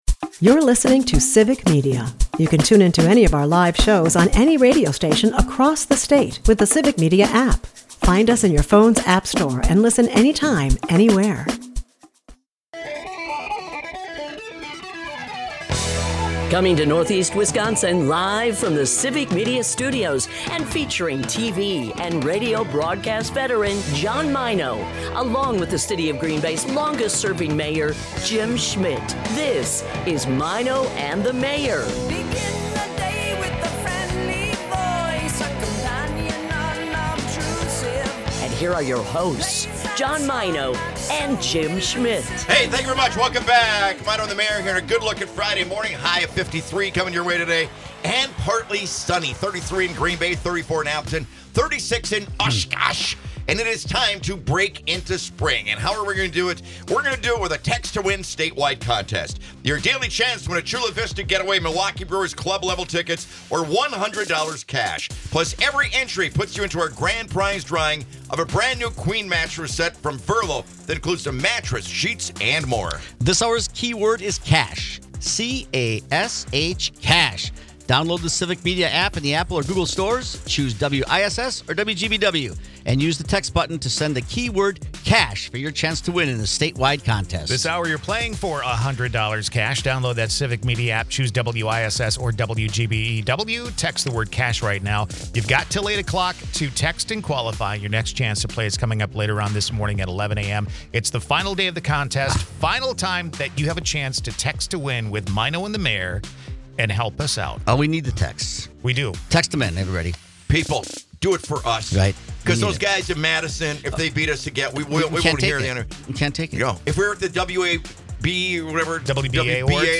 We get a chance to sample some of their great music, and it's awesome!